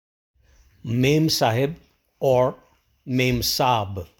Memsahib (MEM-saab)